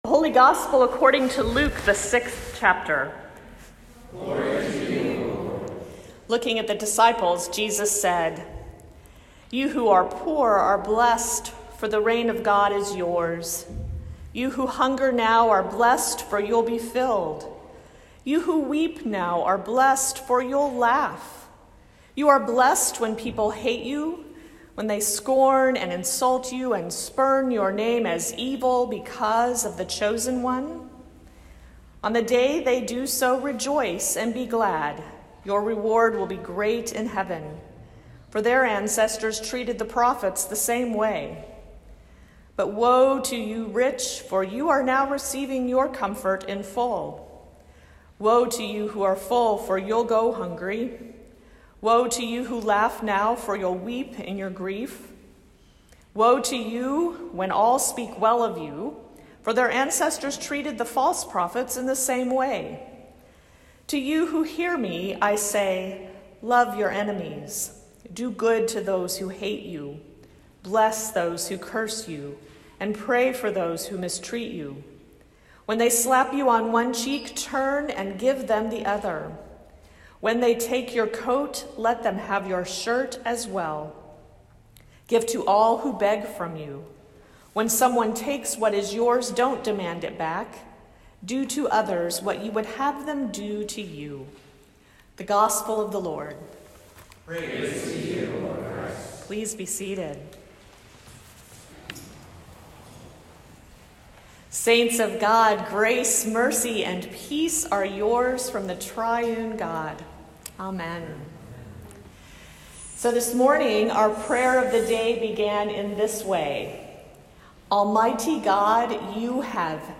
All-Saints-Sunday-2019.mp3